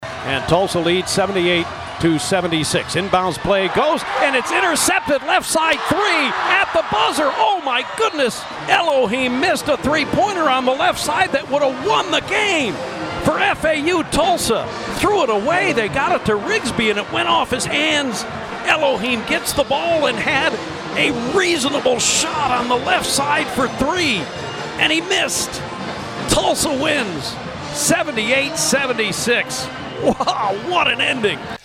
late Owl three wouldn’t fall and TU held on for a big road win, here is how it sounded on KRIG 104.9 FM.